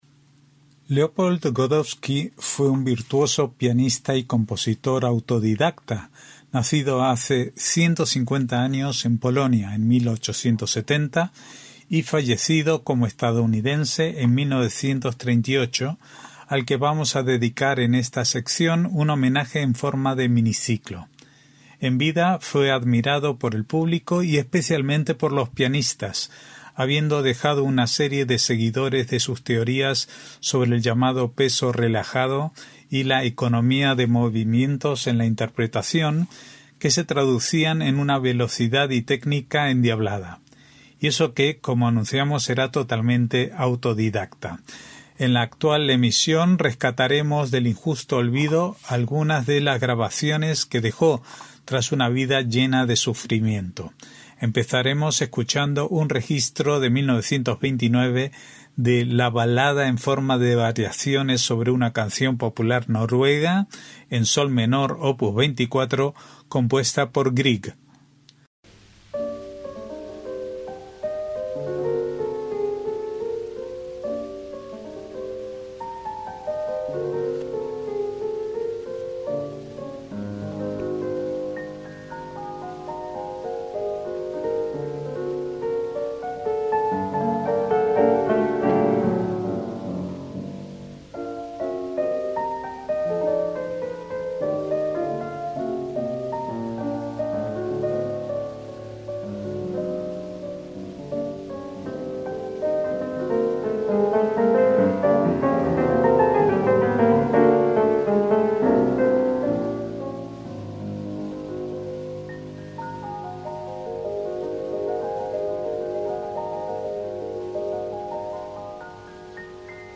MÚSICA CLÁSICA
En la actual emisión rescataremos del injusto olvido algunas de las grabaciones que dejó, tras una vida llena de sufrimiento.